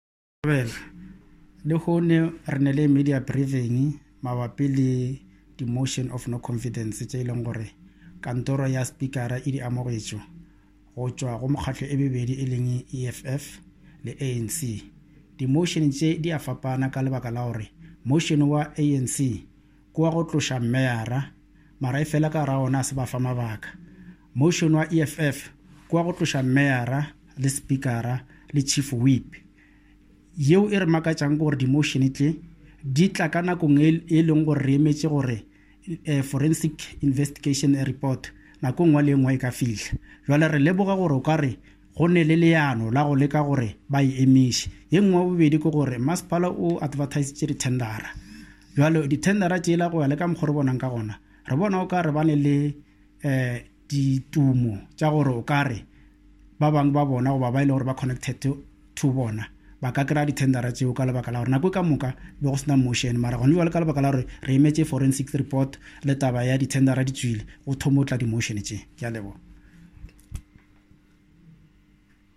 English, Mayor of Modimolle-Mookgophong Municipality, Marlene van Staden, in Afrikaans and Modimolle-Mookgophong Municipality Speaker, Solly Motshwene, in